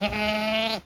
goat_call_04.wav